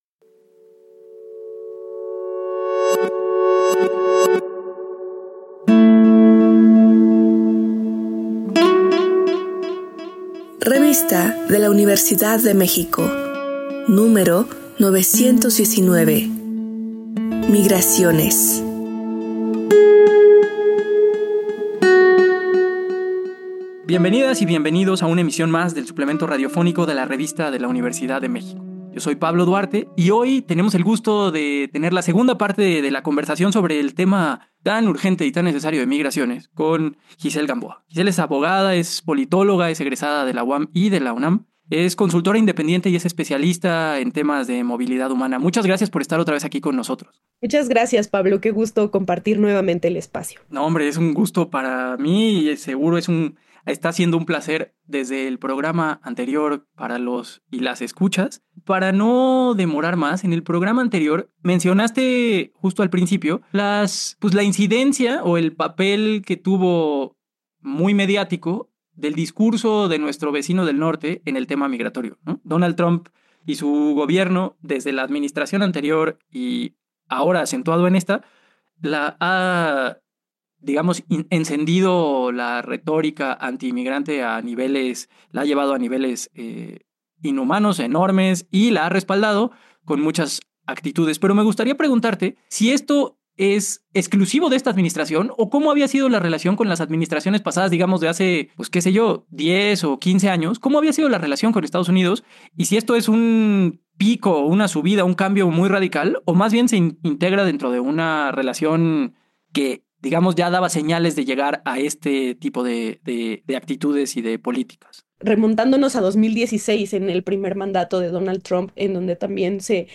Fue transmitido el jueves 24 de abril de 2025 por el 96.1 FM.